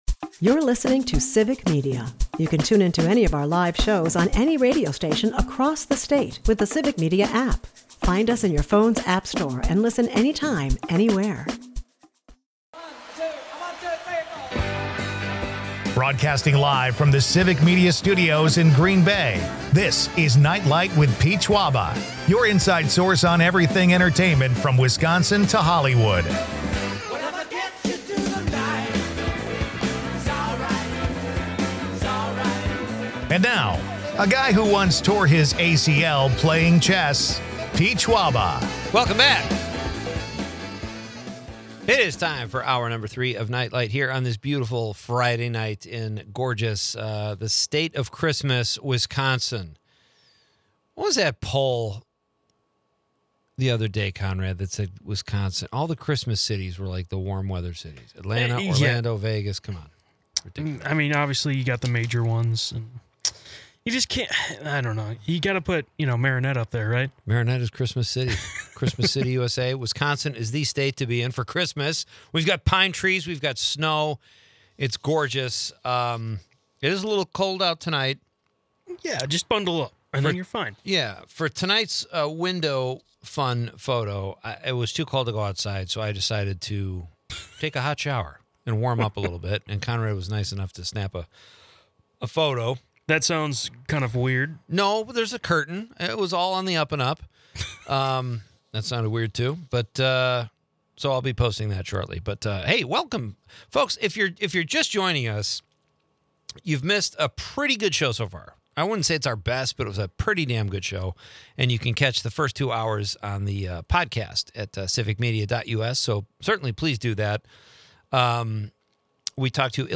The show features spirited debates on Packers vs. Bears, with comparisons between quarterbacks Jordan Love and Aaron Rodgers.
Eggnog divides listeners, sparking a humorous debate.